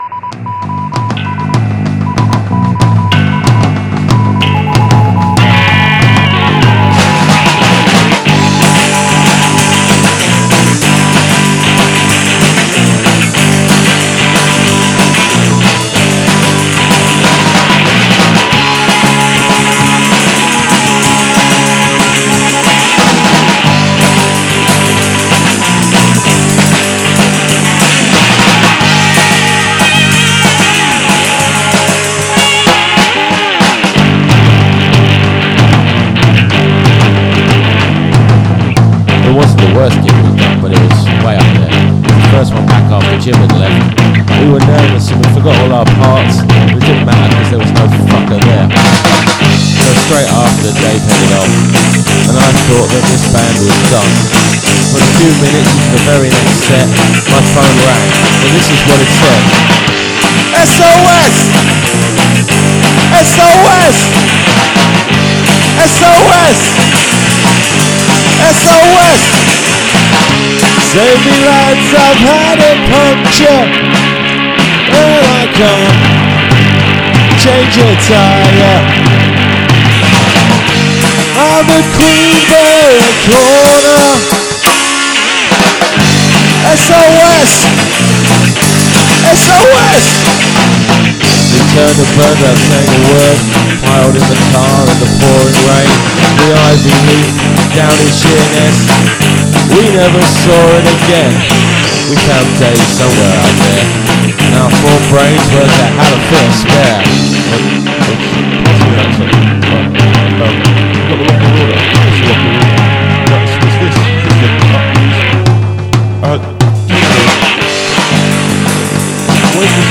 Beautifully chaotic. Chaotically beautiful.